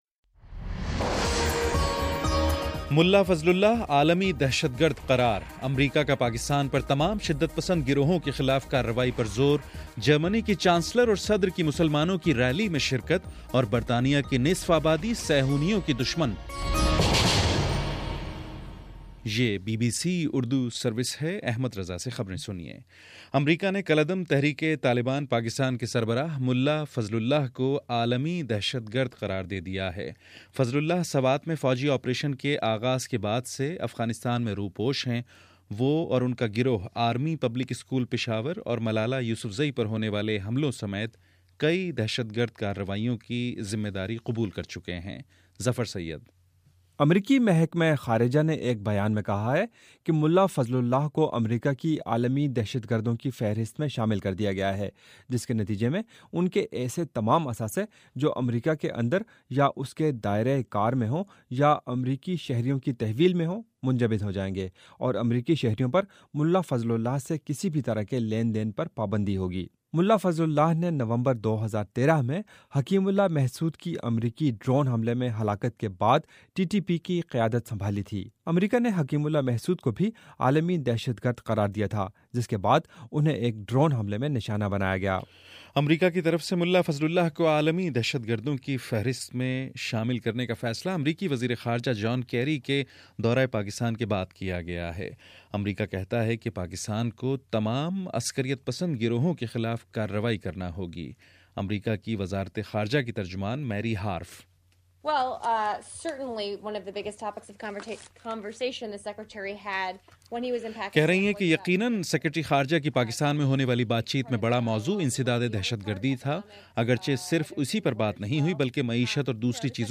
جنوری 14: صبح نو بجے کا نیوز بُلیٹن